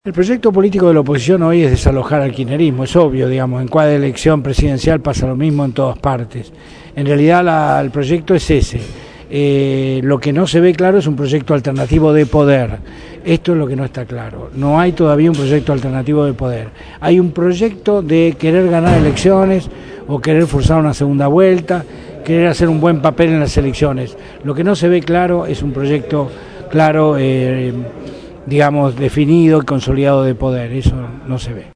Producción y entrevista